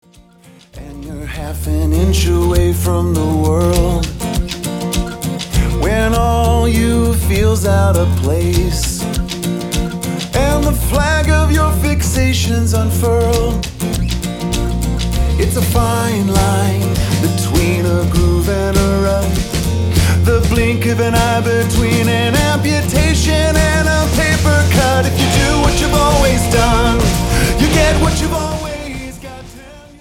CJM (Contemporary Jewish Music)